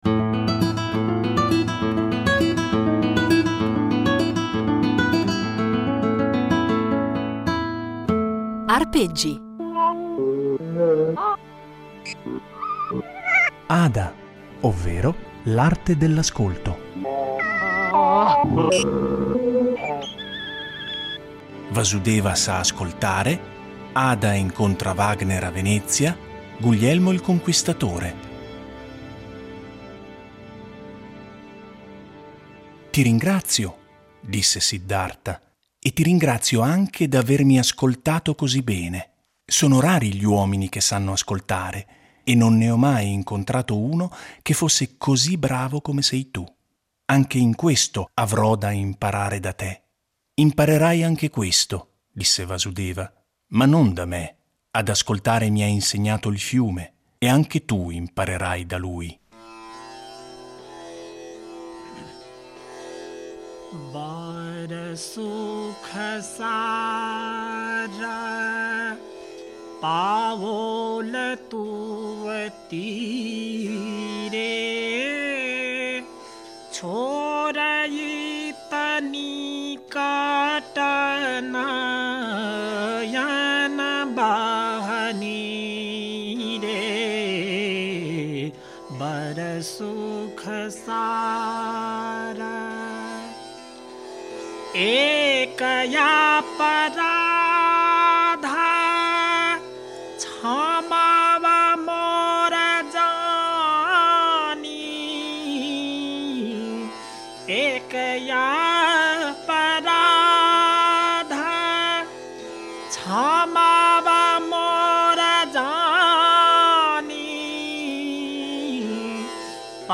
un patchwork fatto di musiche, suoni, rumori e letture